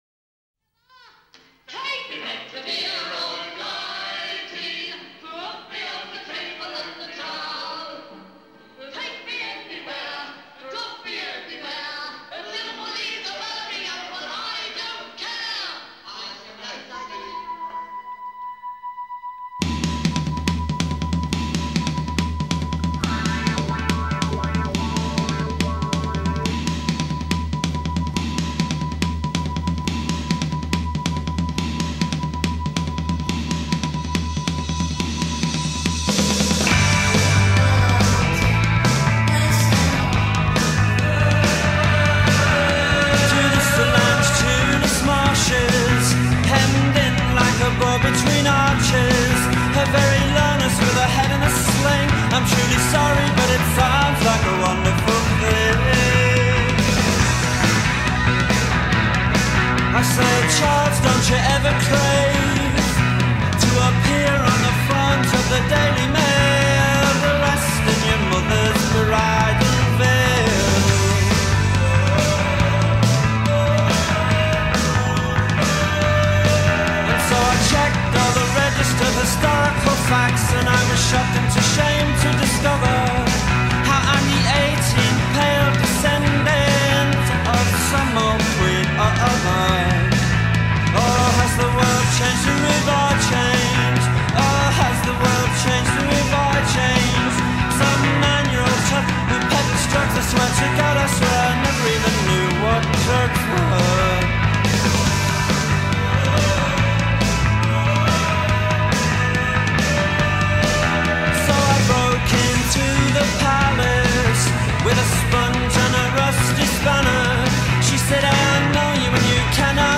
Indie Rock, Post-Punk, Jangle Pop